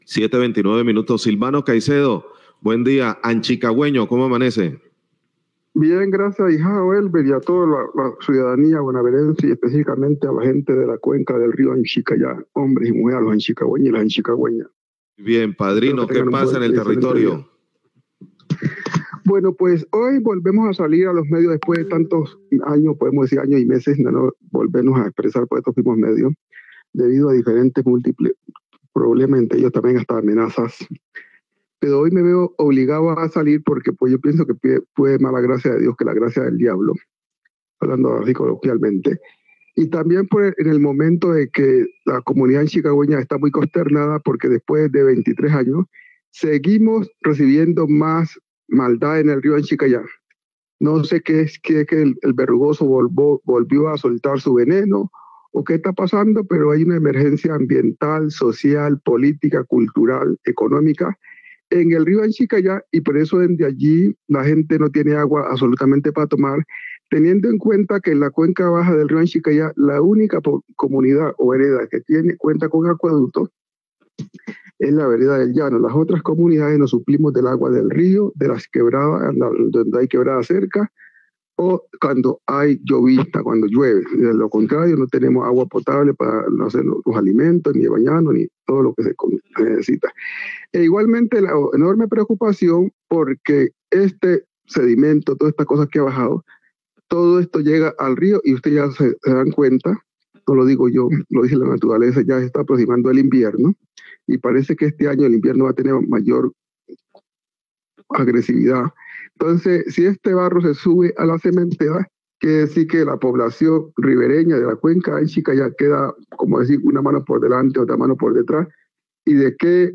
Oyente hace llamado por afectación ambiental del río Anchicayá
Radio